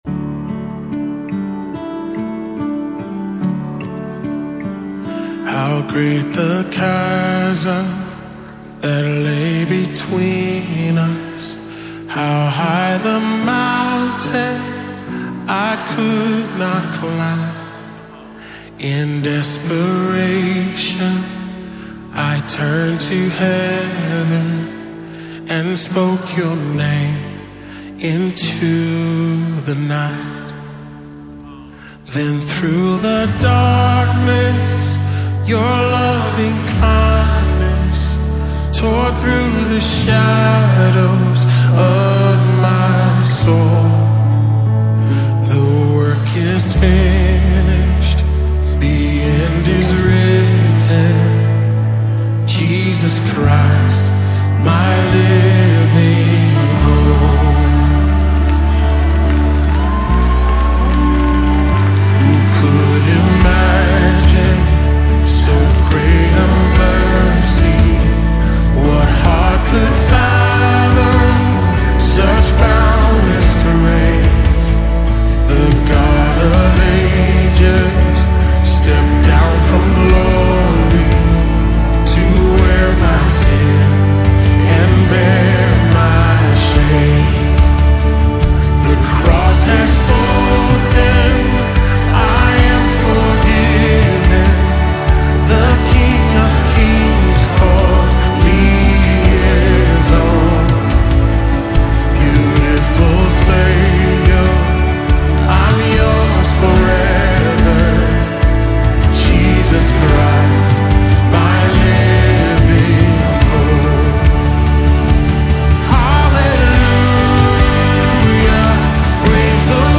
In the ever-evolving soundscape of contemporary worship
Recorded in a live setting